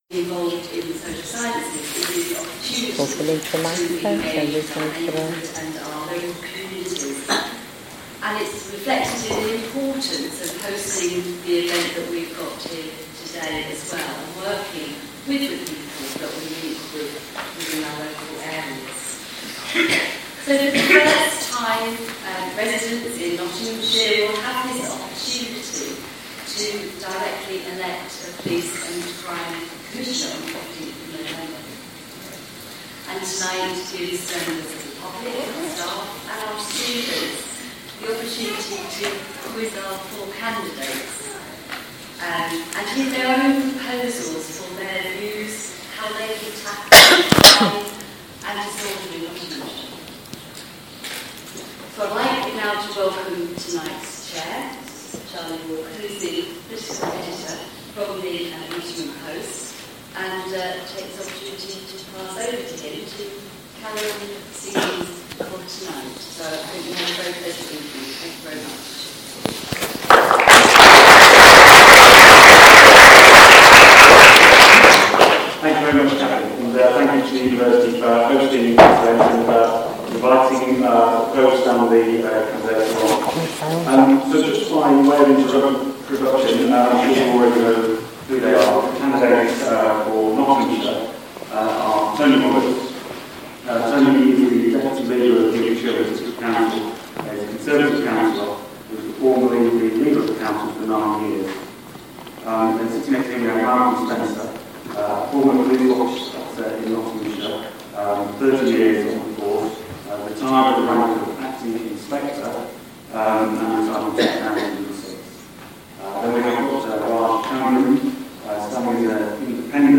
At 5.30pm the 4 Candidates attended hustings for the Police & Crime Commissioners job.